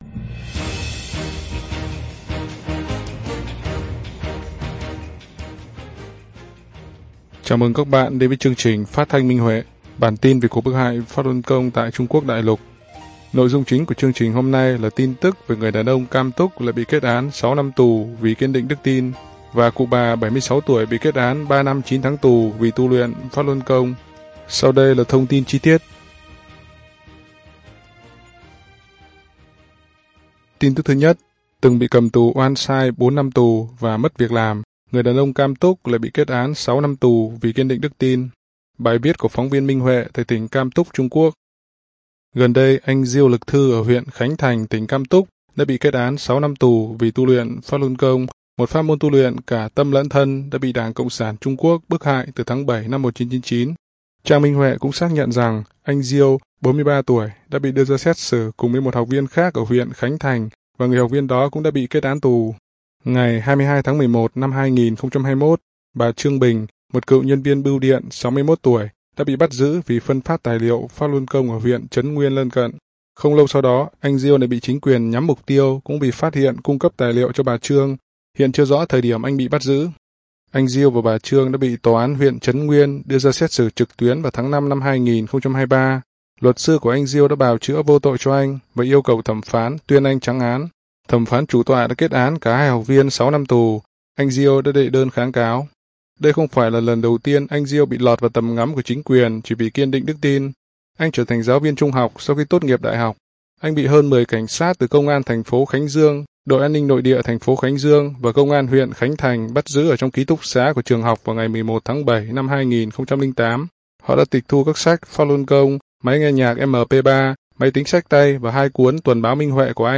Chương trình phát thanh số 20: Tin tức Pháp Luân Đại Pháp tại Đại Lục – Ngày 29/6/2023